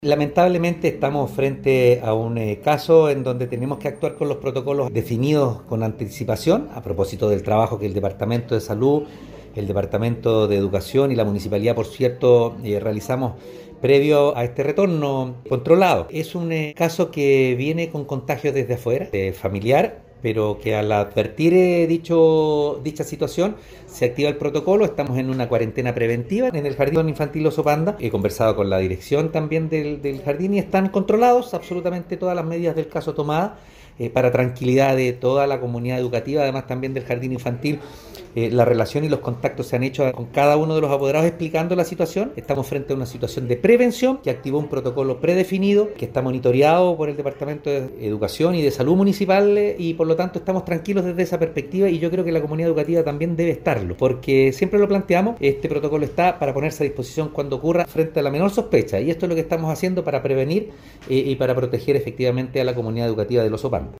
En este sentido, el alcalde Oscar Calderón Sánchez indicó que el contagio se produjo fuera del Jardín y ante la confirmación positiva de este, se aplicaron las medidas que se trabajaron durante estos meses con la RedQ, la Municipalidad y el Departamento de Salud.